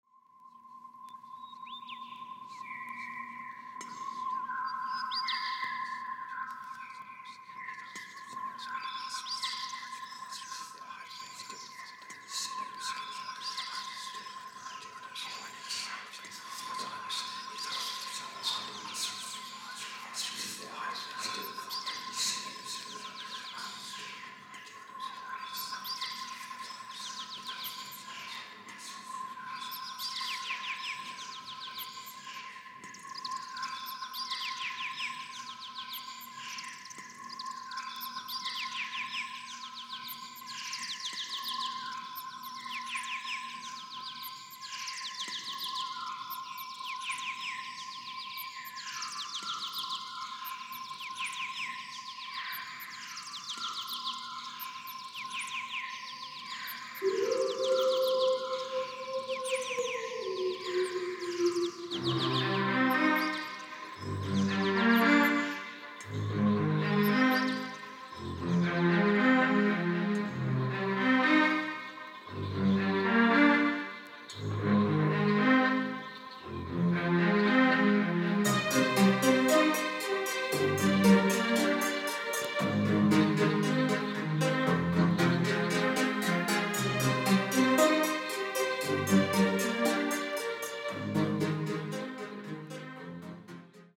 この辺りはCosmicで注目されましたね！！！